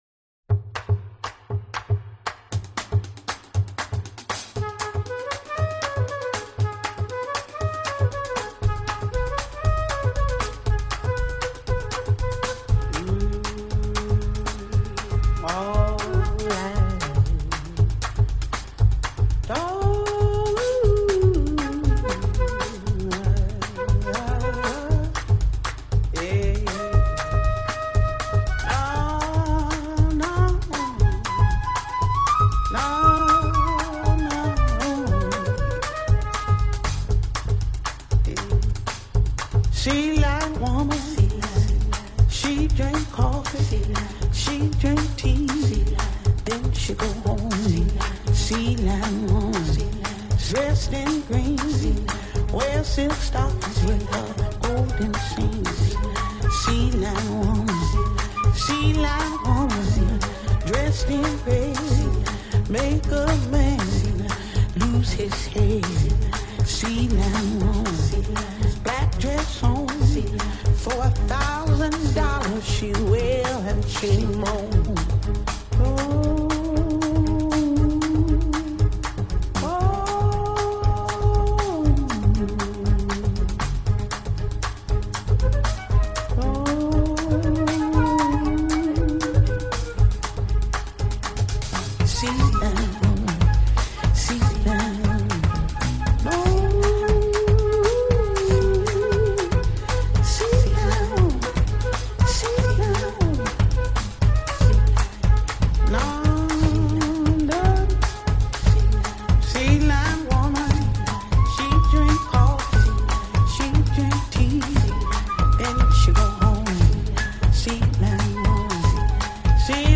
Darüber hinaus stelle ich die These auf, dass das Internet viel gefährlicher ist als Ballerspiele und warum es schlau ist, sich dort (im Internet) nur sporadisch aufzuhalten. Die Musik kommt heute von großartigen Frauen, deren Platten ich aktuell besonders viel und häufig höre.